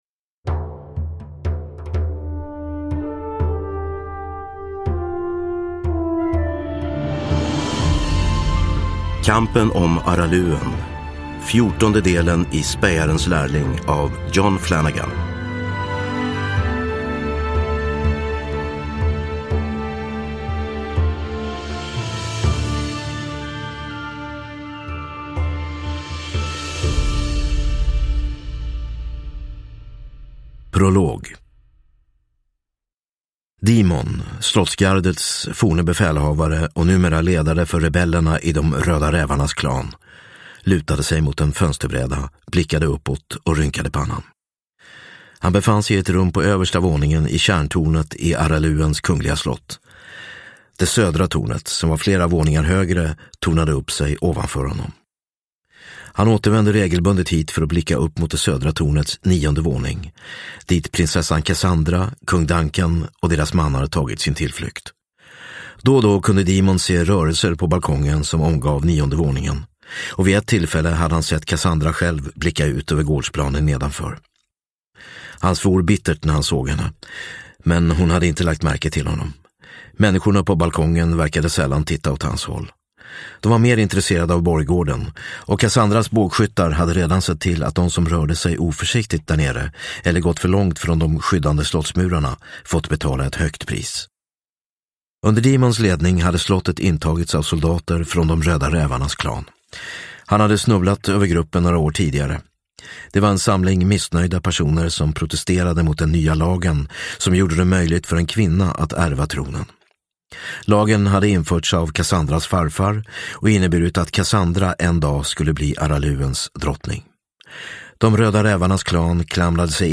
Kampen om Araluen – Ljudbok – Laddas ner